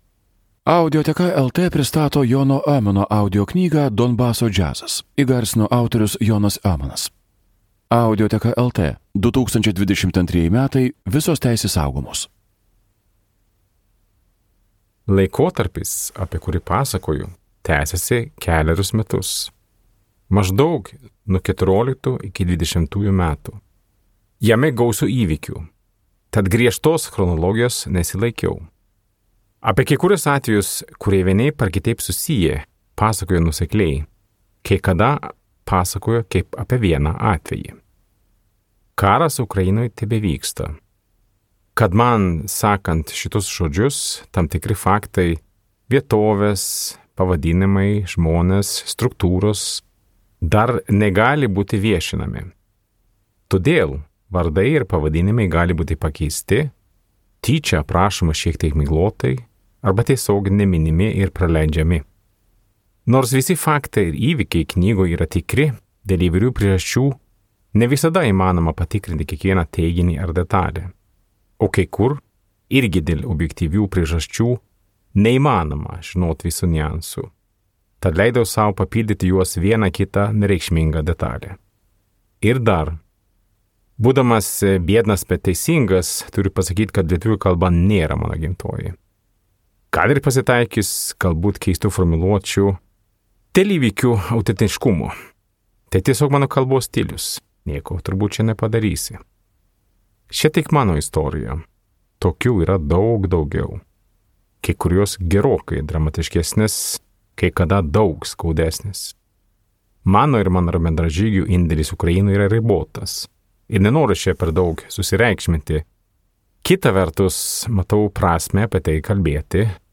Jono Öhman audioknyga „Donbaso džiazas“ atgimsta paties autoriaus balsu. Tai autentiški karo išgyvenimų pasakojimai, atskleidžiantys tikrąją kovos už laisvę kainą.